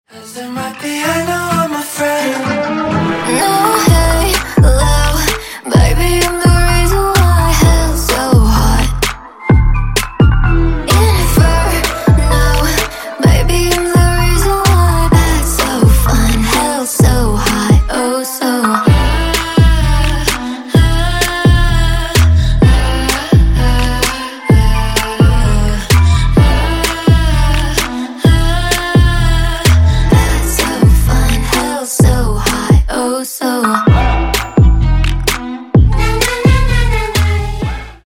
Поп Рингтоны
Скачать припев песни